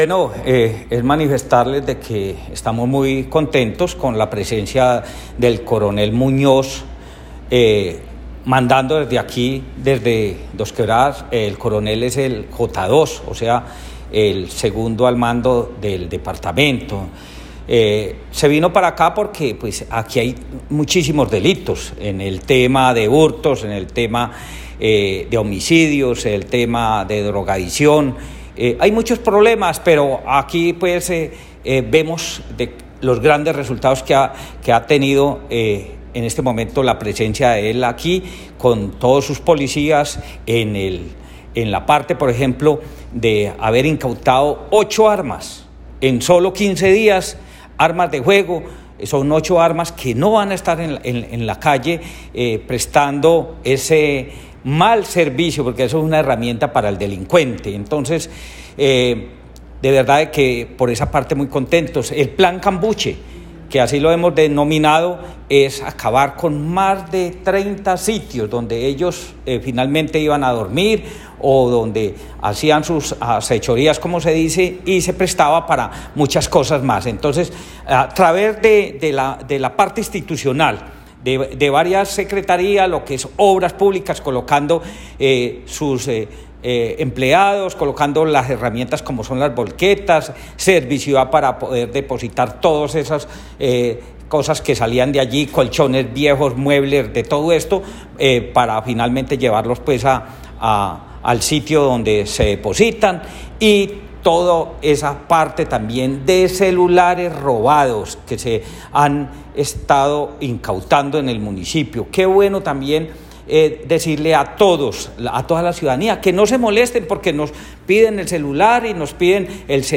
Escuchar Audio: Alcalde Diego Ramos.
Comunicado-038-Audio-Alcalde-Diego-Ramos..m4a